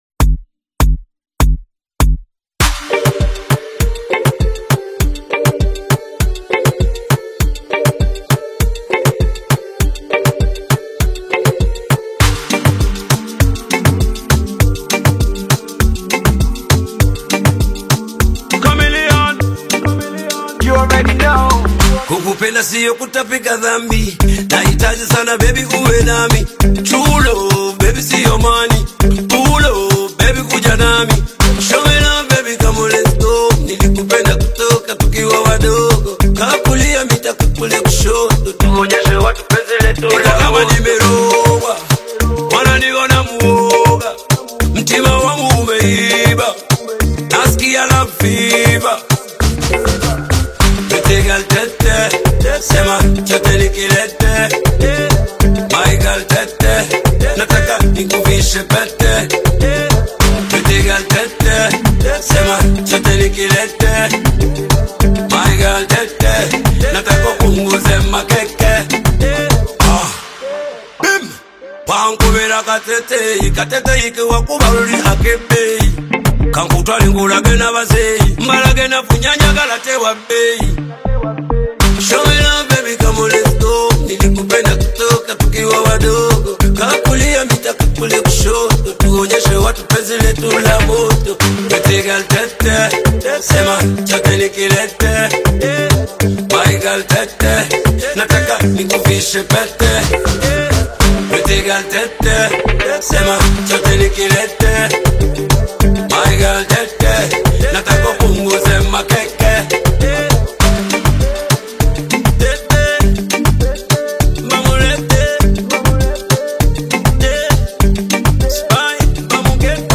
deeply emotional Afrobeat inspired track